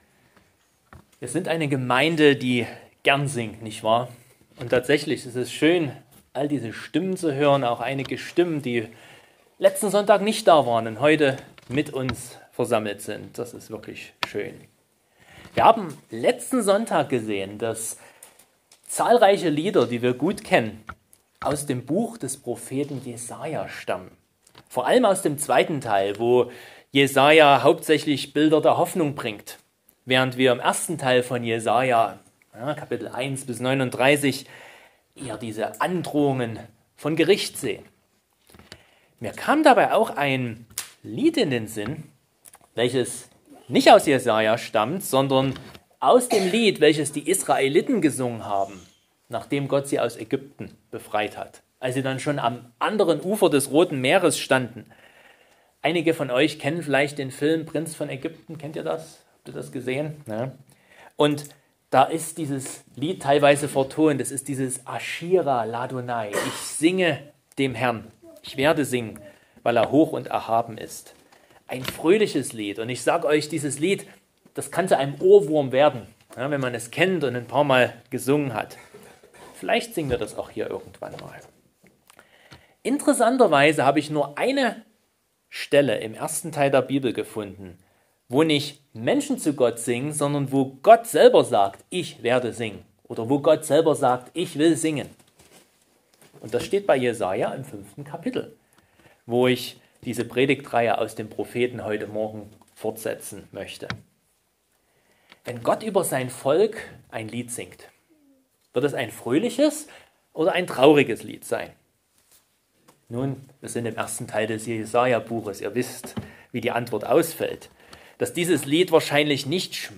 Predigt-Jesaja-02-Arbeiter-in-Gottes-Weinberg.mp3